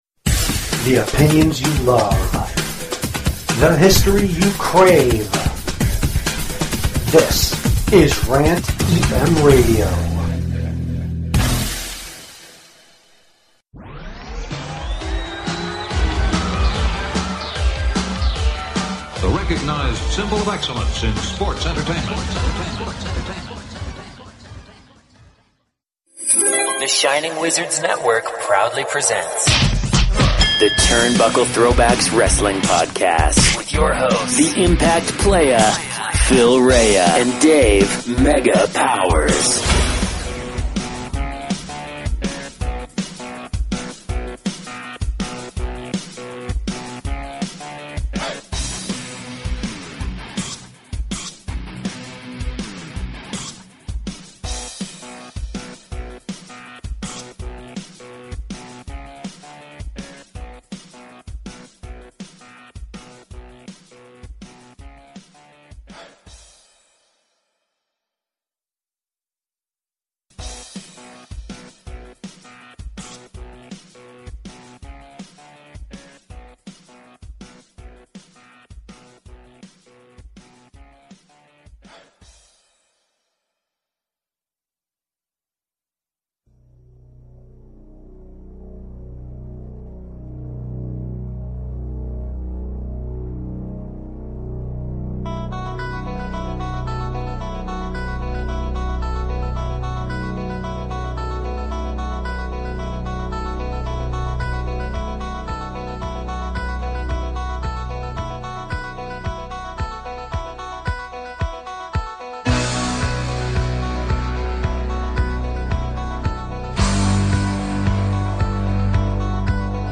can’t get the audio right